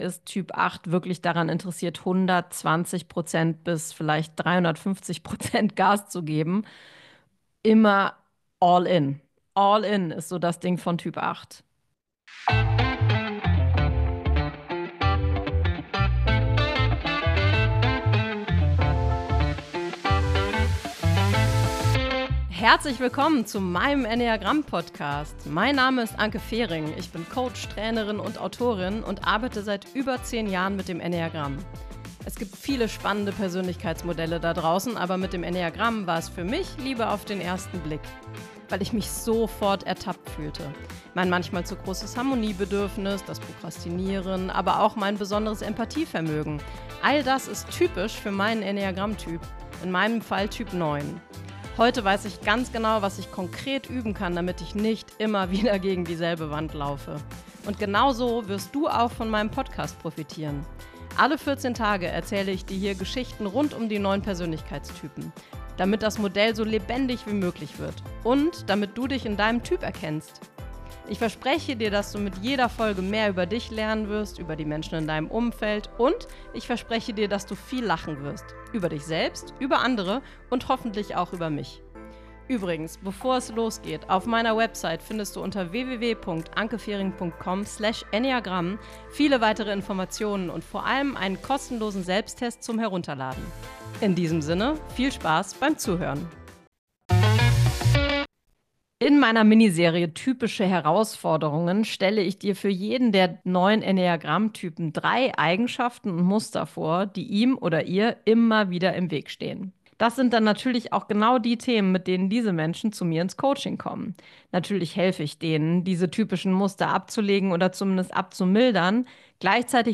Solofolge_Herausforderungen_Typ8.mp3